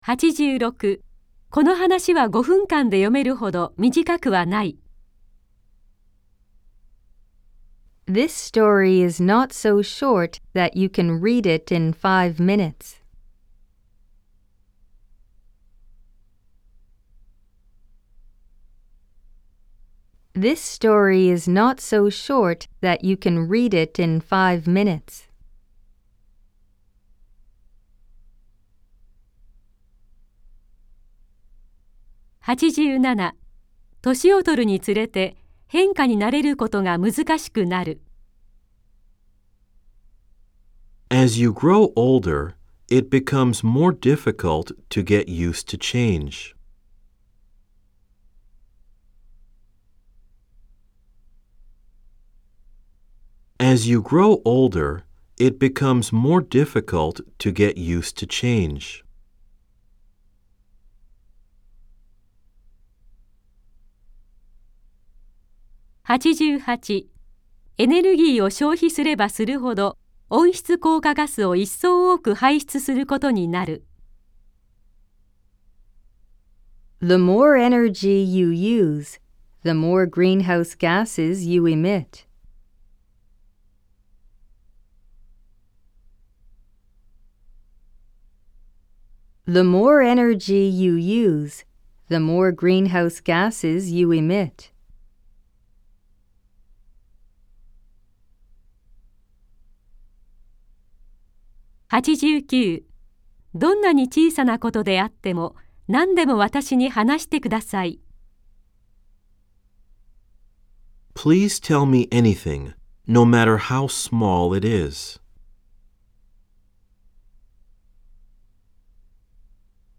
（4）暗唱例文100　各章別ファイル（日本文＋英文2回読み）
※（1）（2）では英文のあとに各5秒のポーズ、（3）（4）では各7秒のポーズが入っています。